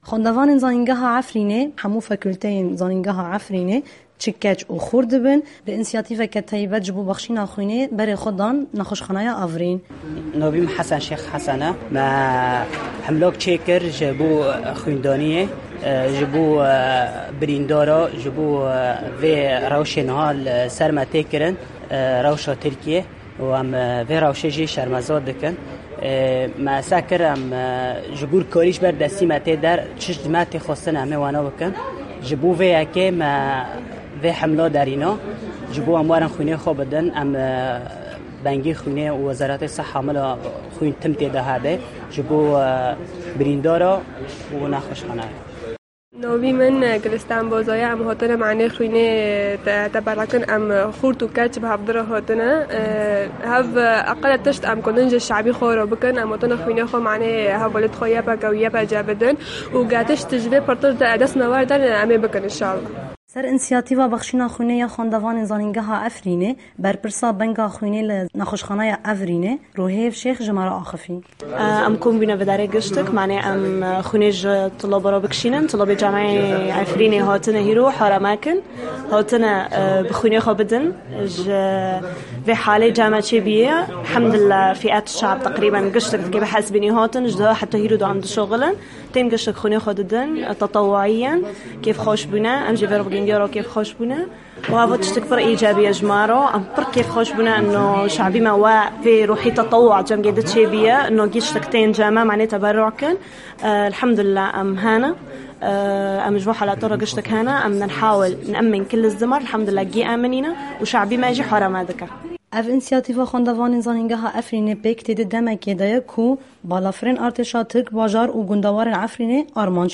دەقی ڕاپۆرتی پەیامنێرمان